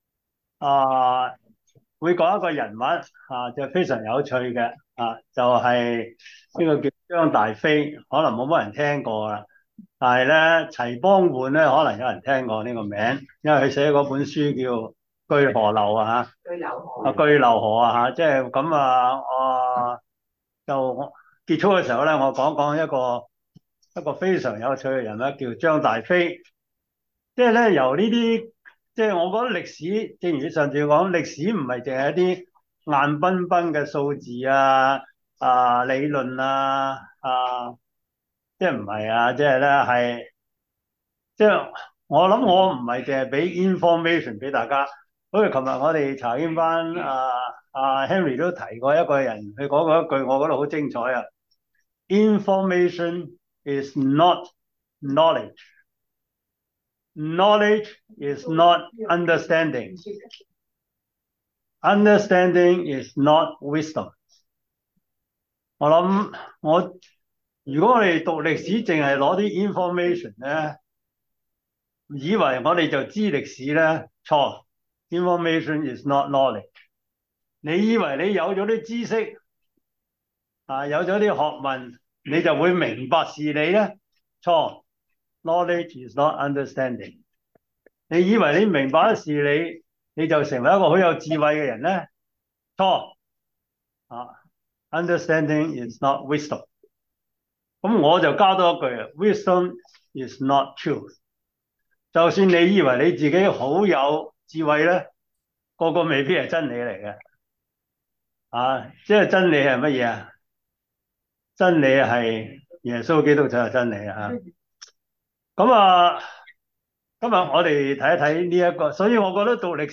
教會歷史 Service Type: 中文主日學 中國教會史